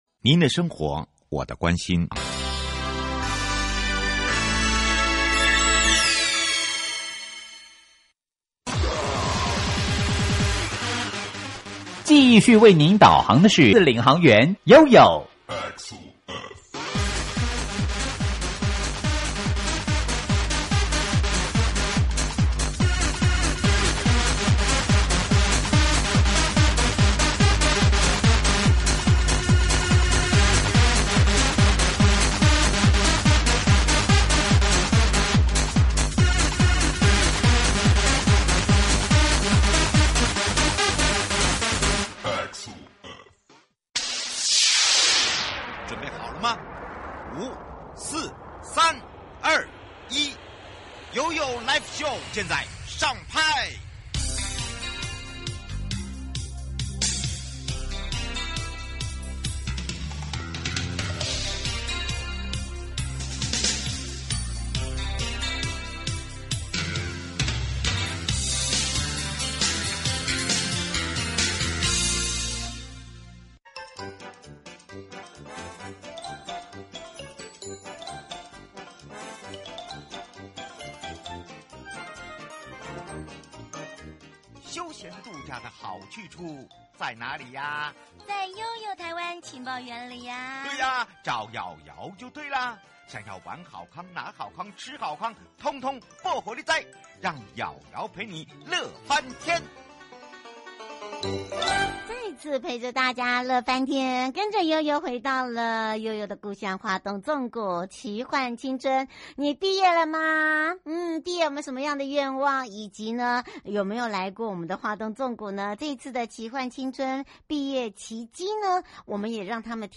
受訪者： 1.花東縱谷管理處 許宗民處長